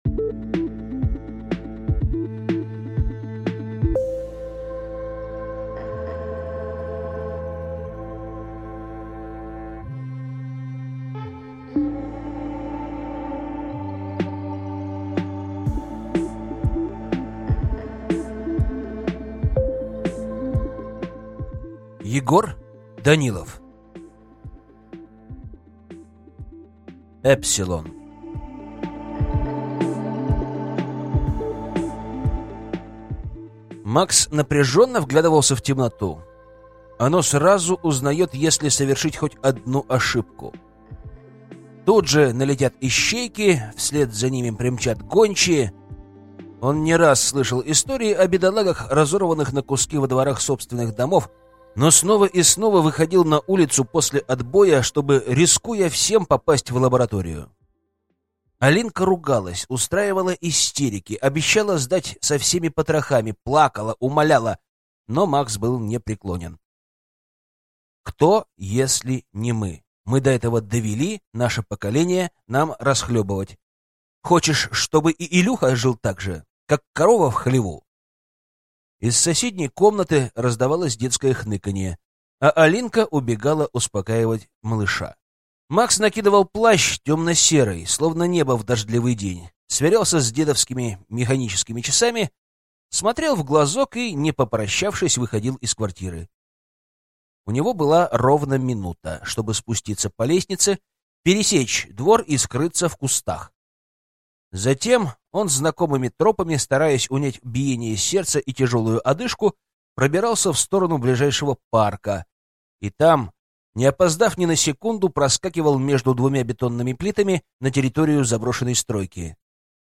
Аудиокнига Эпсилон | Библиотека аудиокниг
Прослушать и бесплатно скачать фрагмент аудиокниги